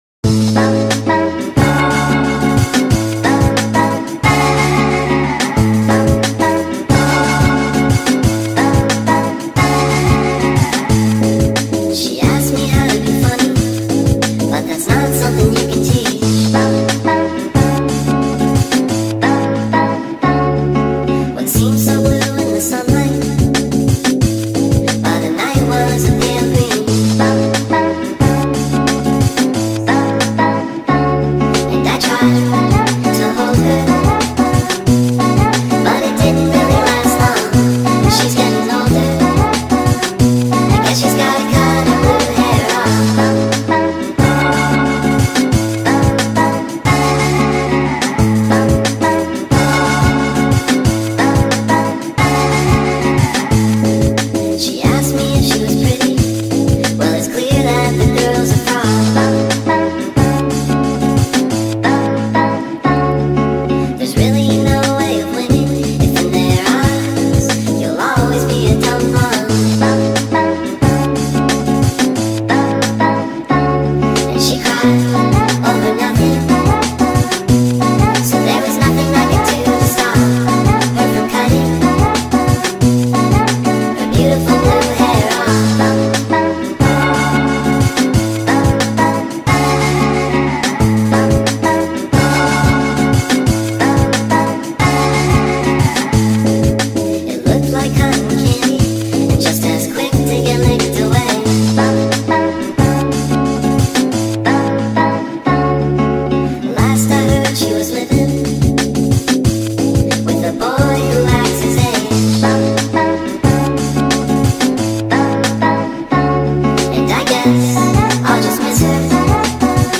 speed up remix